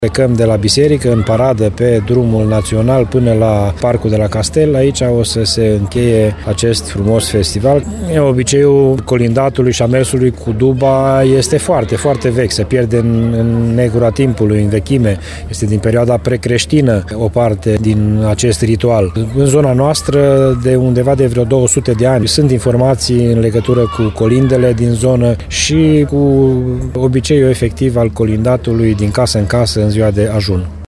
“Alaiul Dubașilor” ajunge la Săvârșin duminică după amiază, între orele 16.30 și 17.30. Manifestarea tradională pe Valea Mureșului reunește șase formații de dubași din județele Arad și Timiș, spune primarul comunei Săvârșin, Ioan Vodicean, la rândul său fost dubaș.